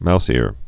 (mousîr)